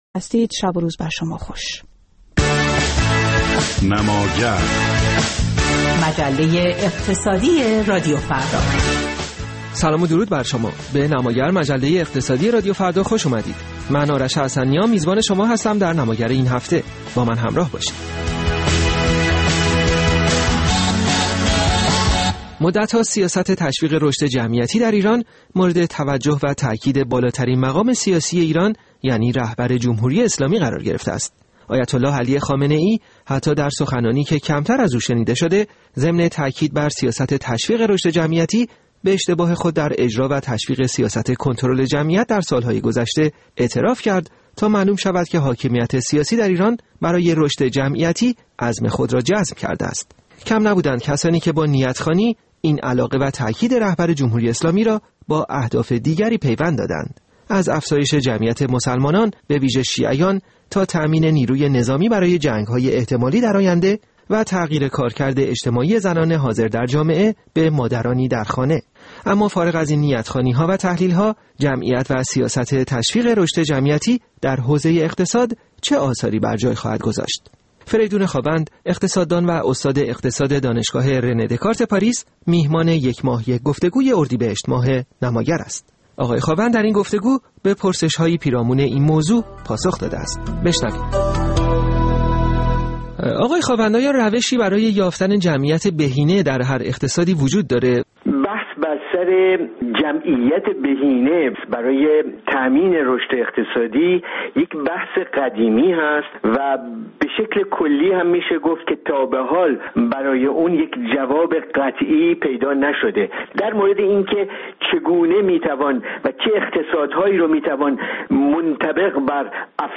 رمزگشایی از جمعیت و پیوند آن با اقتصاد، موضوع گفت‌وگوی ماه نماگر، مجله اقتصادی رادیو فرداست.